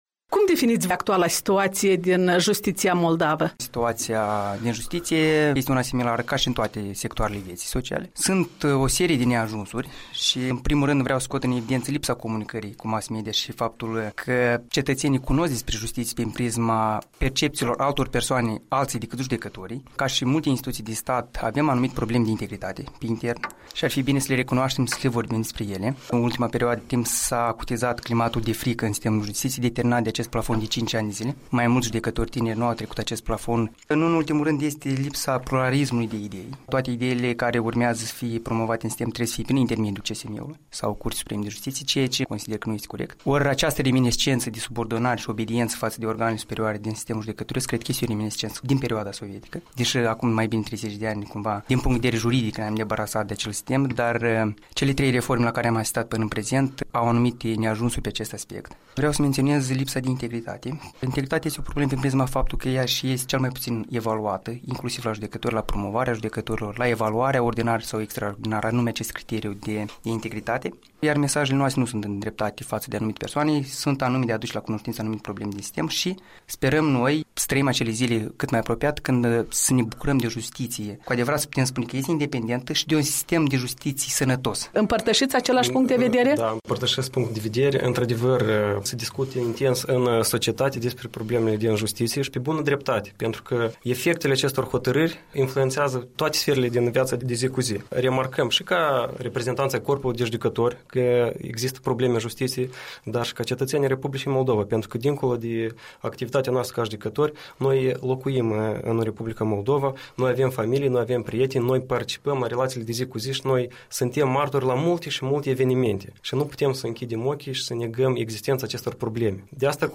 Discuție cu magistrații Ion Chirtoacă și Alexei Paniș